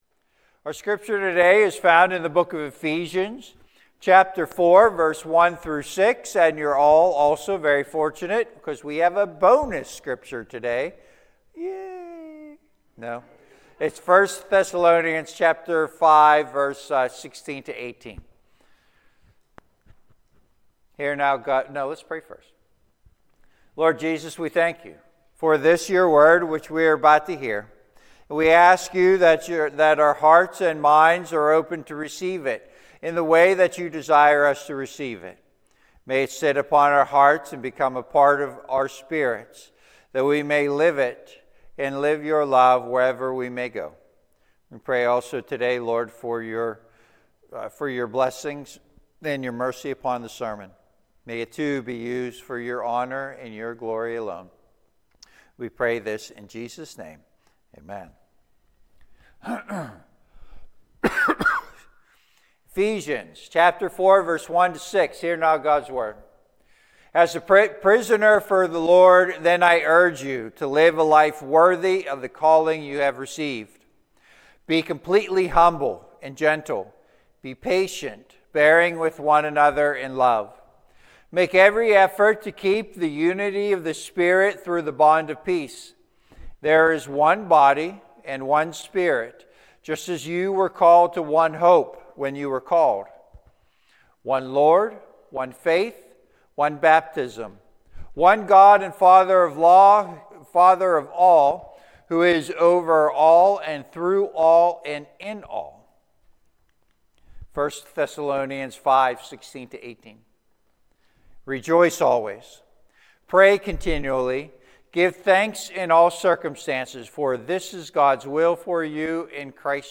Passage: Ephesians 4: 1-6 Service Type: Sunday AM « Born By Grace